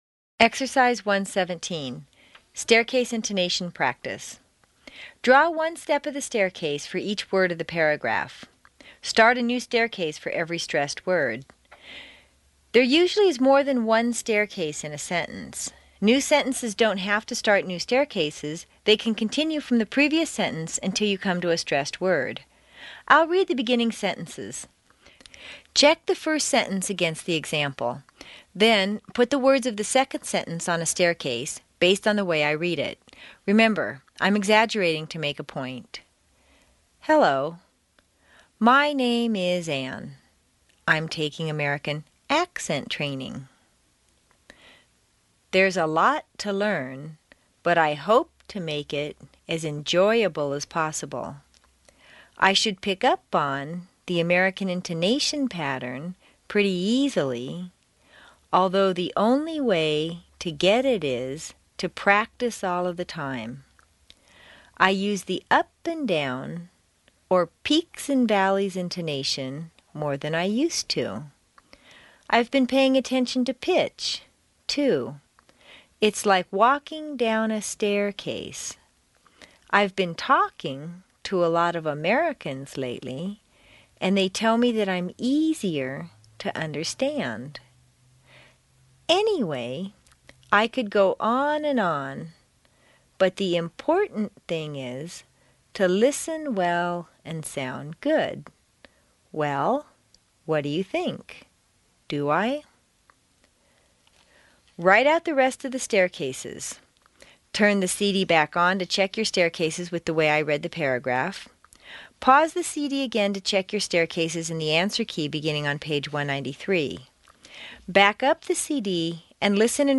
Remember, I'm exaggerating to make